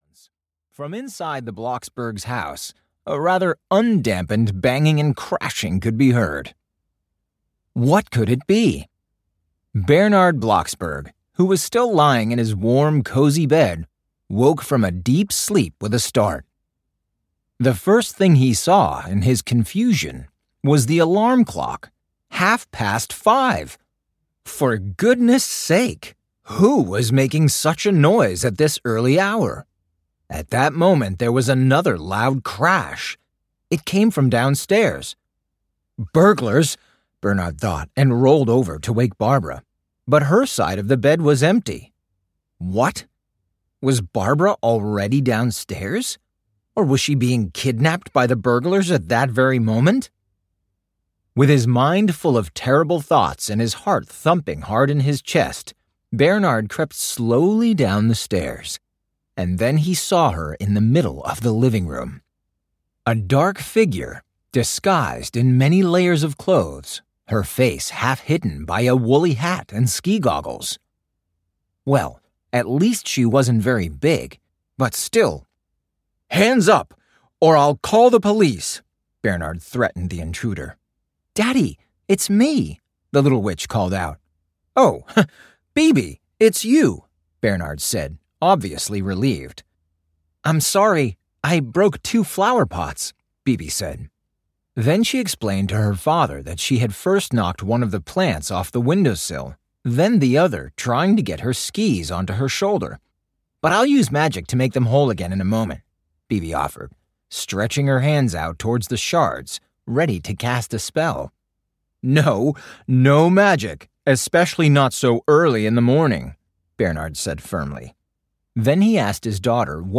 Audiobook Bibi Blocksberg - Bibi and the Ice Witch.
As one of the most successful children's audio drama series, Bibi Blocksberg has been awarded several gold and platinum records in Germany.©2023 KIDDINX Studios, Licensed by Kiddinx Media GmbH, Berlin